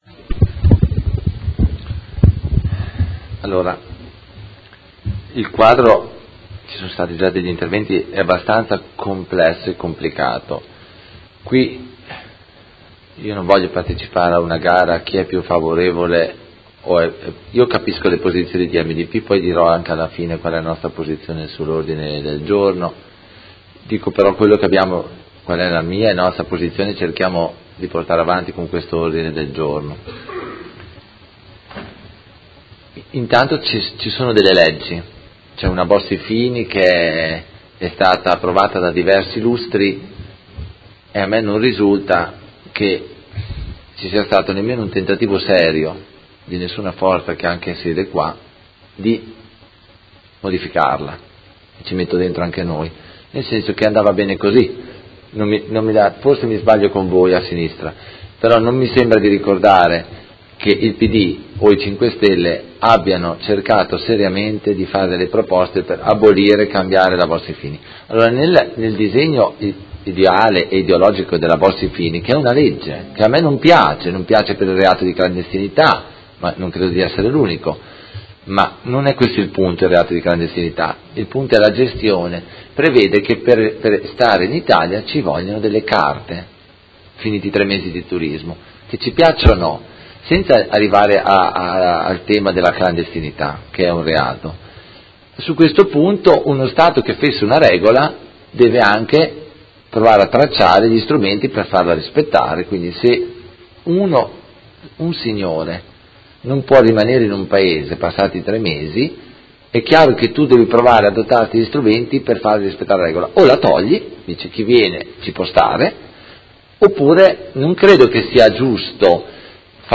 Antonio Carpentieri — Sito Audio Consiglio Comunale
Seduta del 12/04/2018 Dibattito. Ordine del giorno 54394, Emendamento 54490 e ordine del giorno 54480 sulla sicurezza.